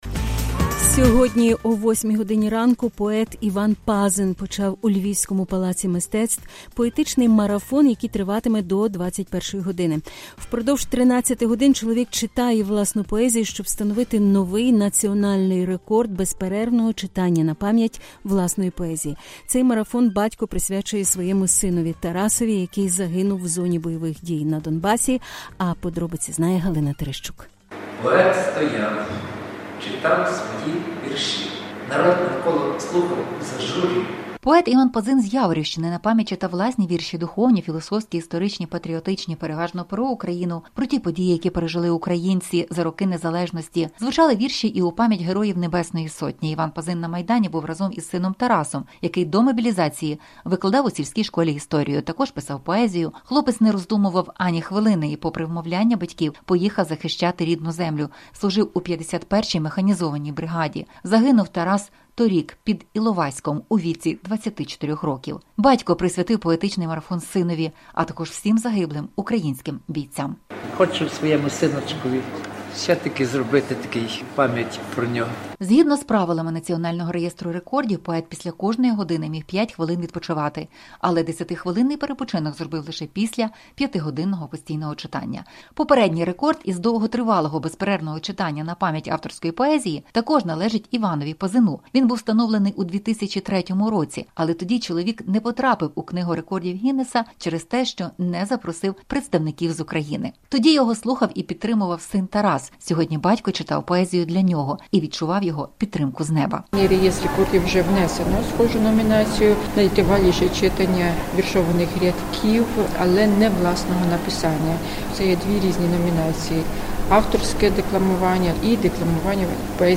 Упродовж понад 12 годин чоловік читав власну поезію, щоб встановити новий національний рекорд безперервного читання напам’ять власної поезії.